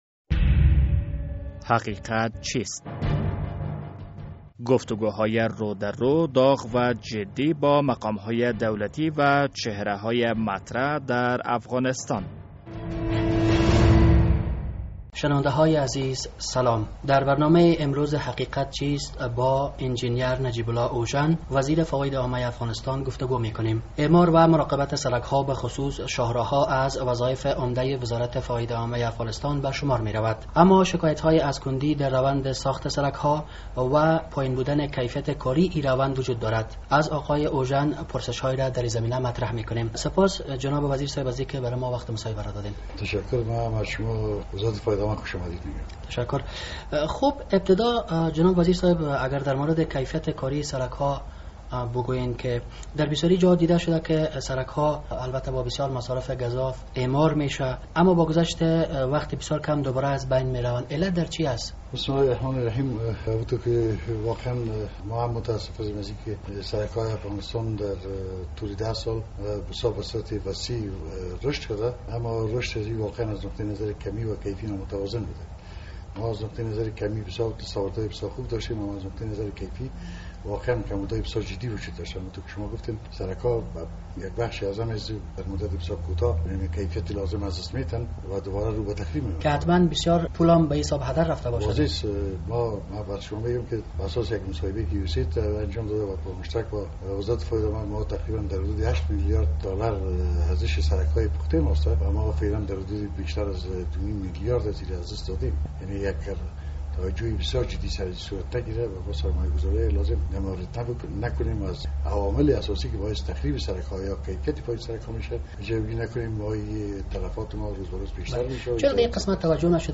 در این برنامهء حقیقت چیست، با نجیب الله اوژن وزیر فواید عامه افغانستان صحبت کرده ام. آقای اوژن در مورد عوامل مشکل سرک ها و شاهراه های افغانستان و اقدامات این اداره صحبت کرده است....